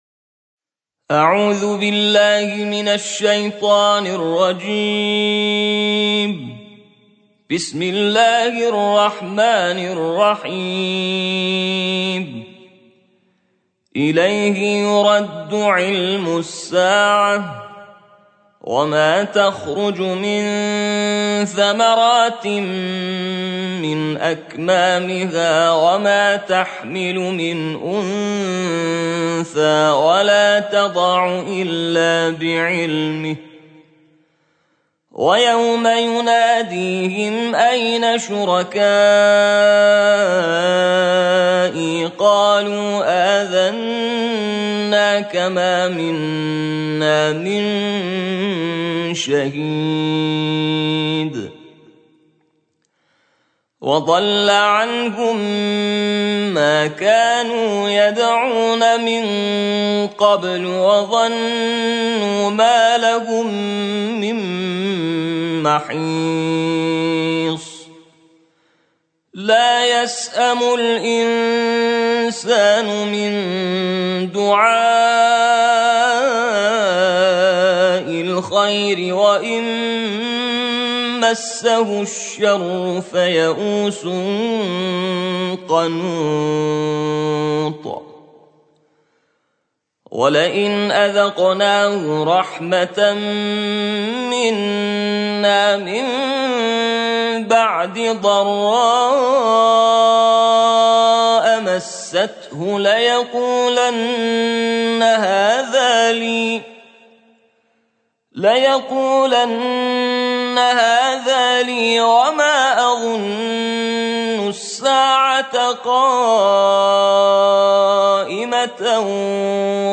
ترتیل جزء 25 قرآن